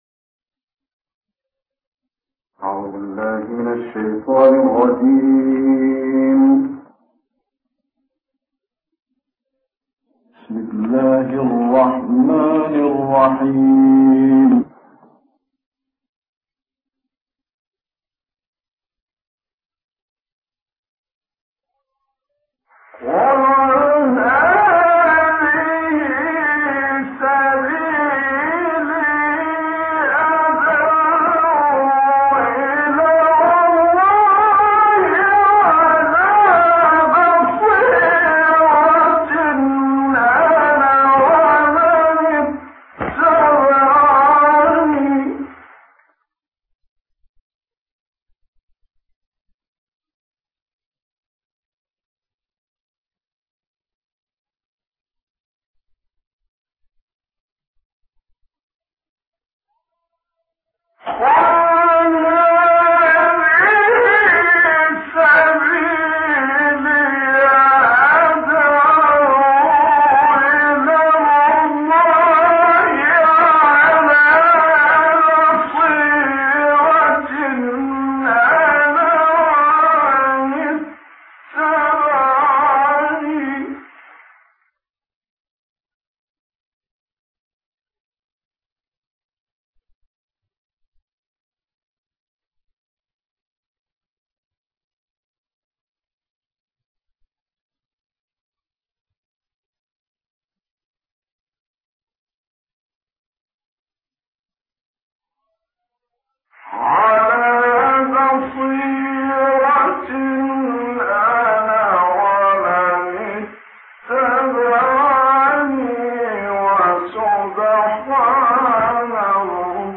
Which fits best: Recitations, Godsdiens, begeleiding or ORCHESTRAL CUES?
Recitations